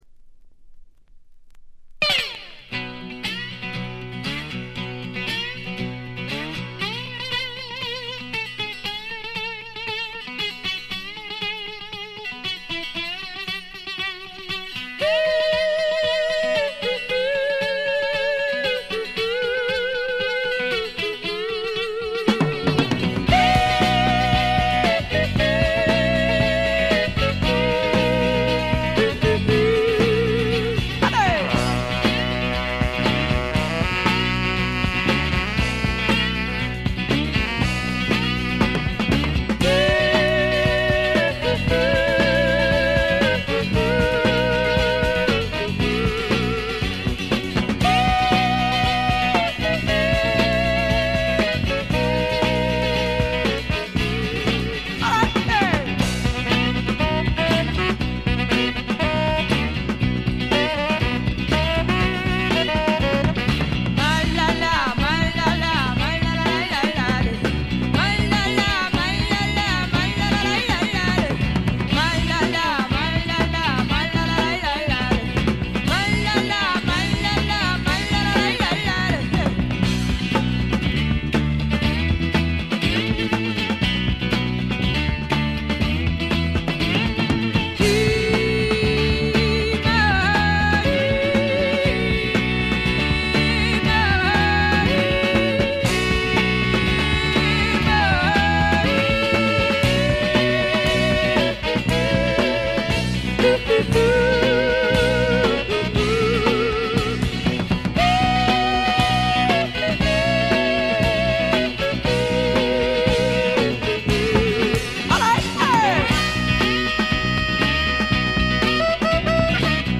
A1冒頭で軽いプツ音1回。
試聴曲は現品からの取り込み音源です。
Recorded At: The Record Plant East, New York City.